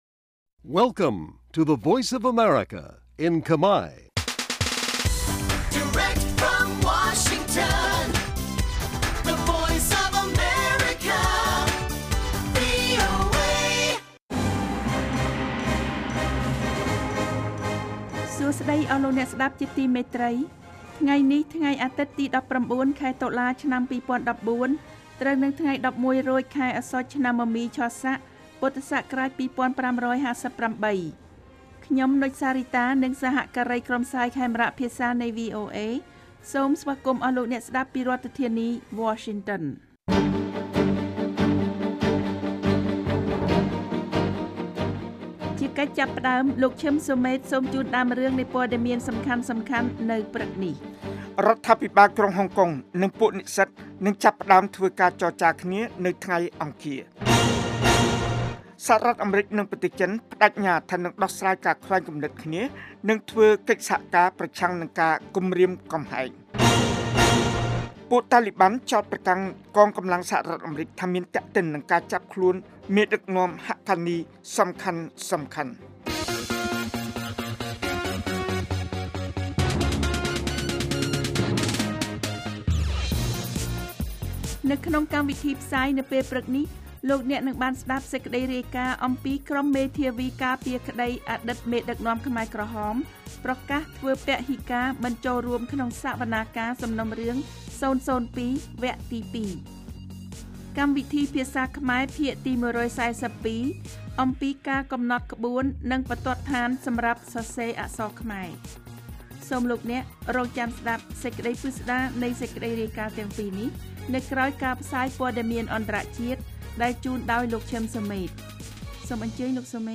This daily 30-minute Khmer language radio program brings news about Cambodia and the world, as well as background reports, feature stories, and editorial, to Khmer listeners across Cambodia.